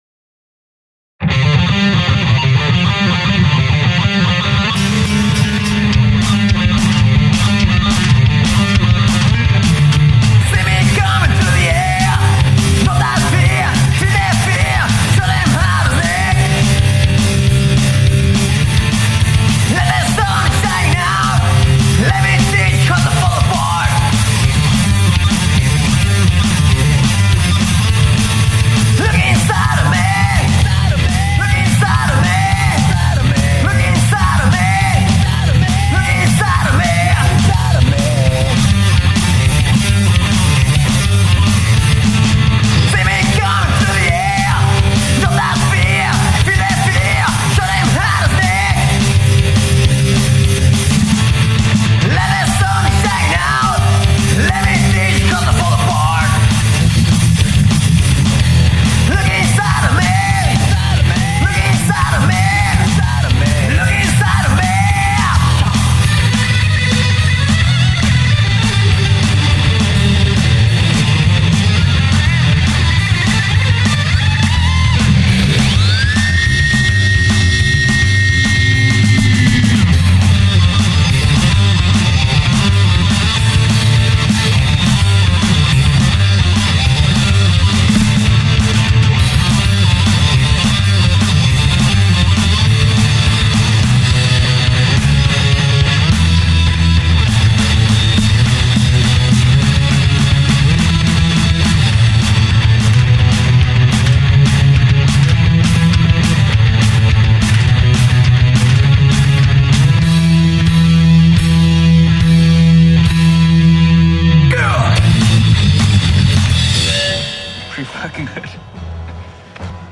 Las canciones se grabaron en diferentes estudios